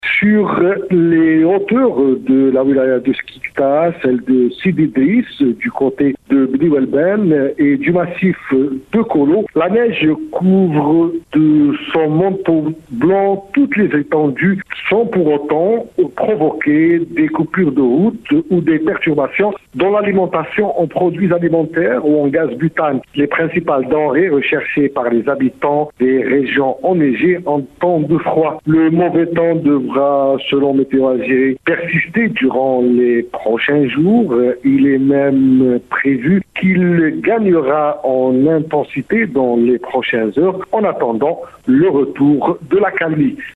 Correspondance, depuis Skikda,